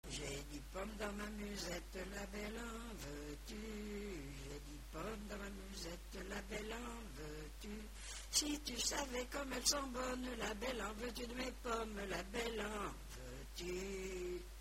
Île-d'Yeu (L')
Genre énumérative
Pièce musicale inédite